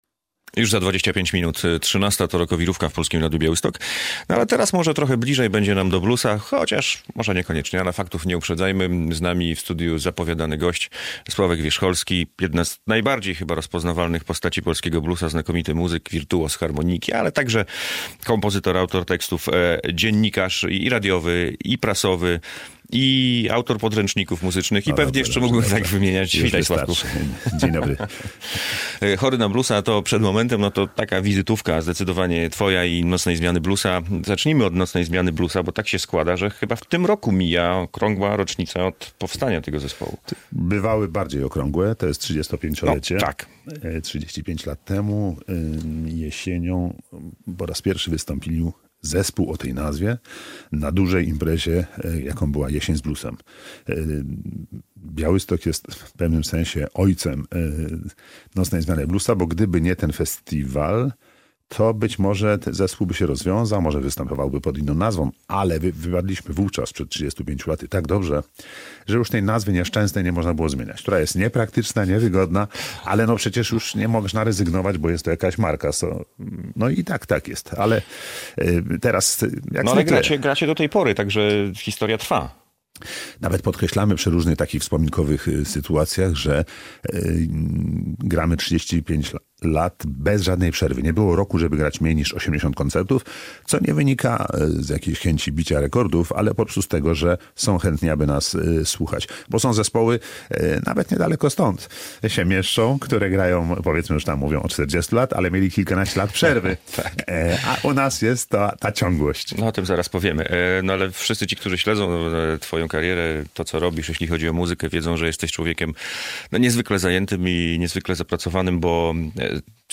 Radio Białystok | Gość | Sławomir Wierzcholski - muzyk bluesowy